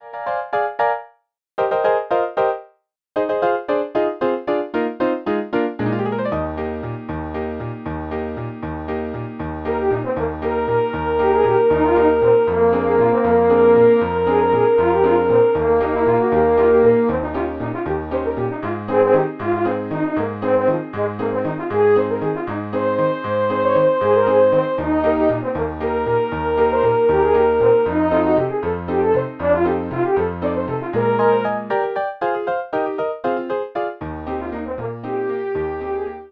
two horns & piano